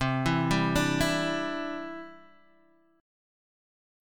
Cadd9 Chord